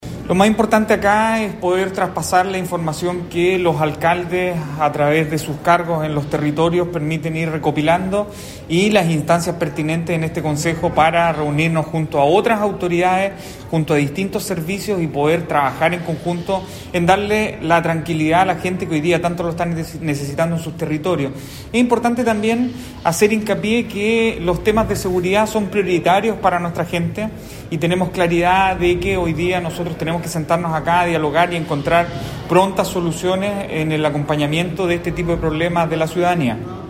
Así lo remarcó el Alcalde de Coquimbo, Alí Manouchehri, quien sostuvo que
Ali-Manouchehri-Alcalde-de-Coquimbo.mp3